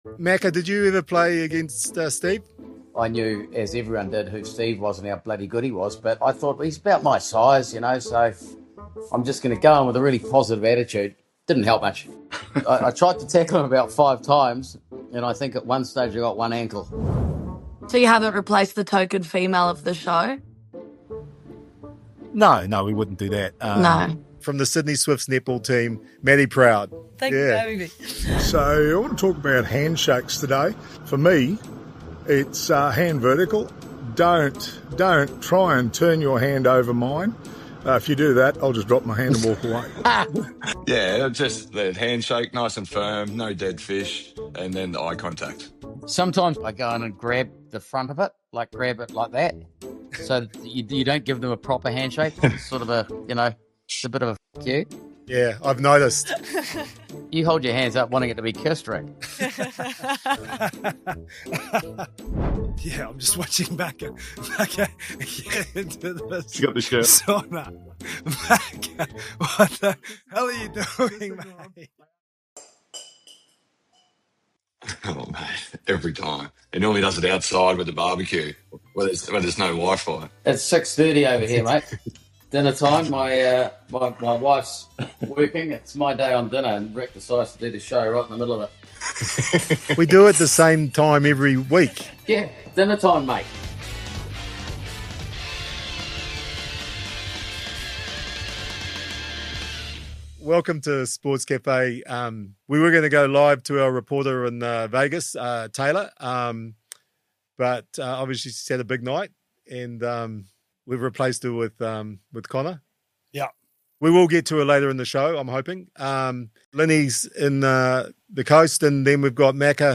NRL legend Steve Renouf joins Sportscafe to break down the biggest talking points from the opening round of the 2026 NRL season.